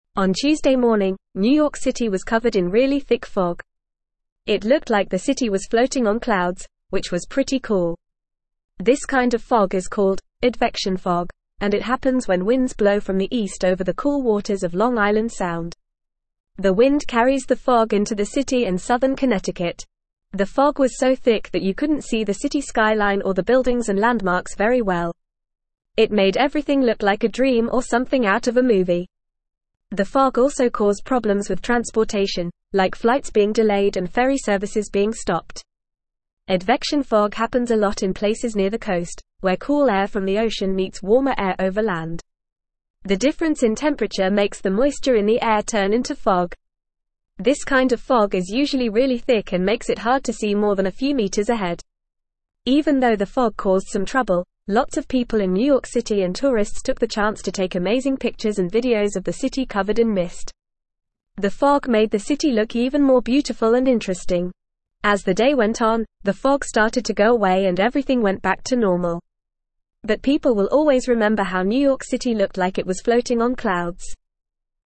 Fast
English-Newsroom-Upper-Intermediate-FAST-Reading-Dense-fog-blankets-New-York-City-skyline.mp3